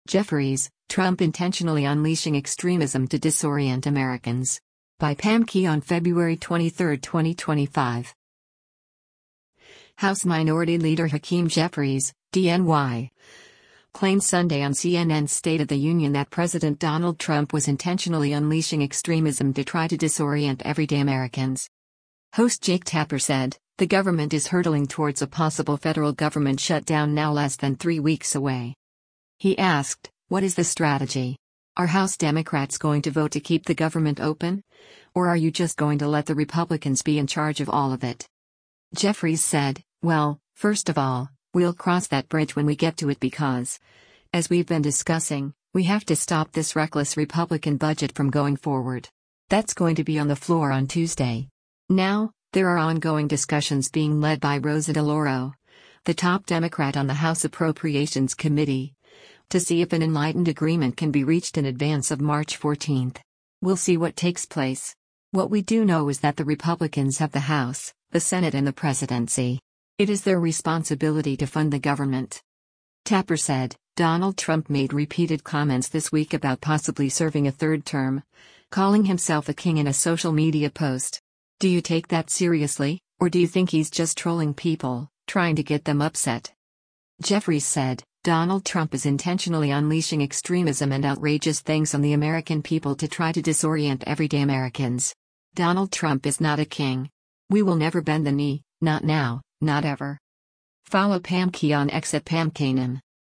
House Minority Leader Hakeem Jeffries (D-NY) claimed Sunday on CNN’s “State of the Union” that President Donald Trump was “intentionally unleashing extremism” to try to disorient everyday Americans.
Host Jake Tapper said, “The government is hurtling towards a possible federal government shutdown now less than three weeks away.”